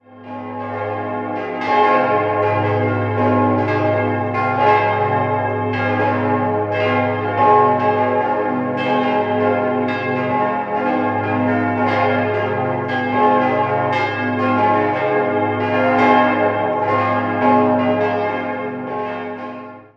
Jahrhunderts zurückgeht. 5-stimmiges erweitertes Salve-Regina-Geläute: as°-c'-es'-f'-as' Die Glocken wurden 1931 von Hamm (5), 1961 von Perner (4), 1814 von Sammassa (3), 1938 von Grassmayr und 2003 von Perner (1) gegossen.